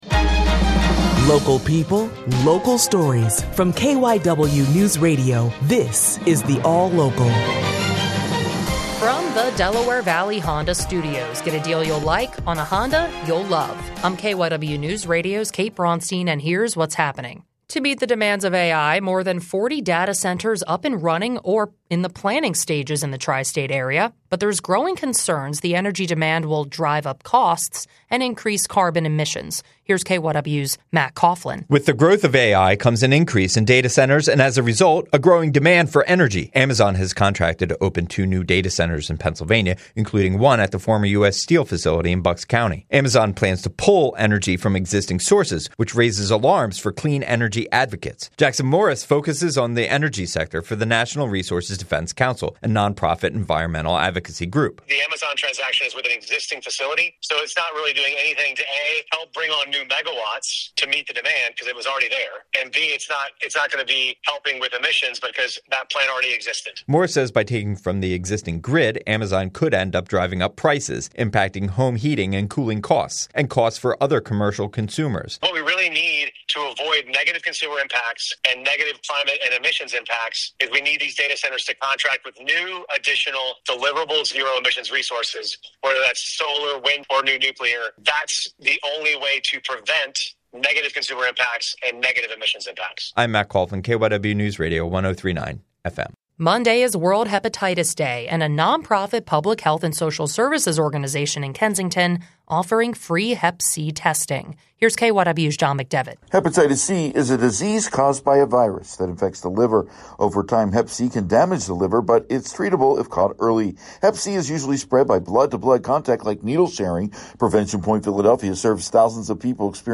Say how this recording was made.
reports from the scene